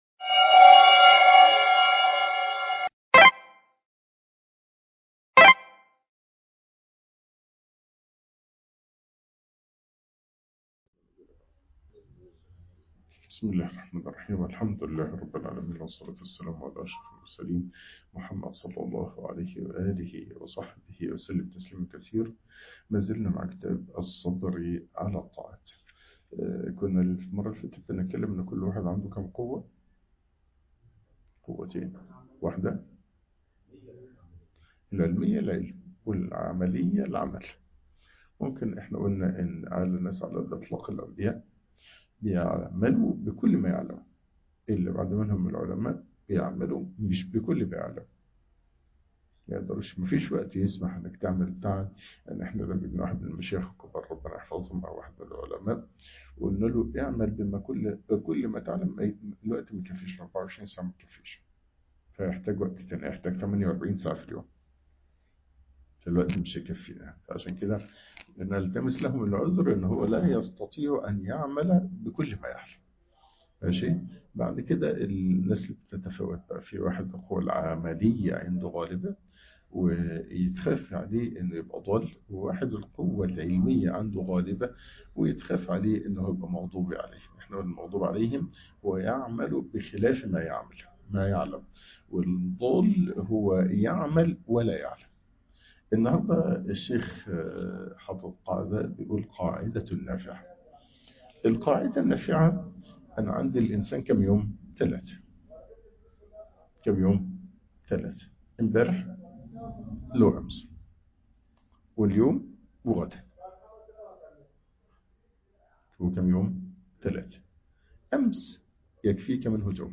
الصبر أعظم الطاعات والقربات - الدرس الخامس (الصبر على الطاعة)